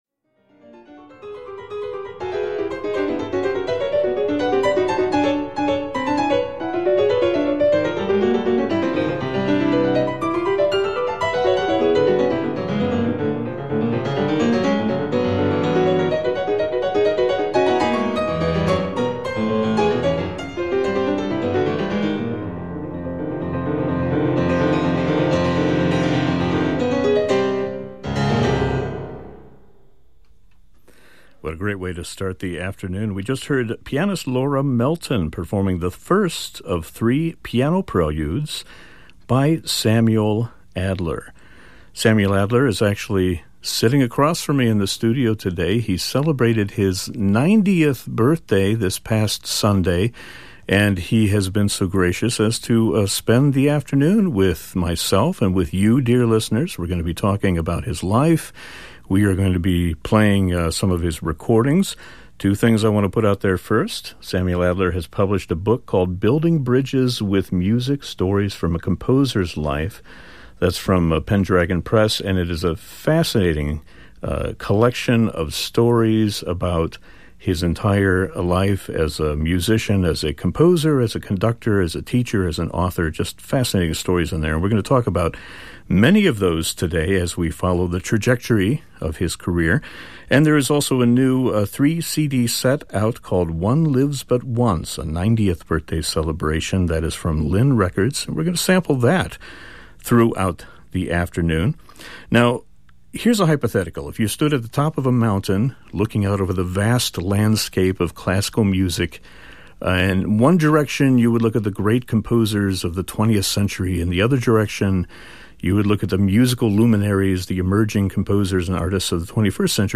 Sam joins us for an extended conversation about his life, career, and music – with a focus on the recent album One Lives but Once: A 90th Birthday Celebration from Linn Records, and Sam's recent autobiography Building Bridges With Music: Stories from a Composer's Life from Pendragon Press. Note: due to copyright, musical selections have been edited out of this podcast audio.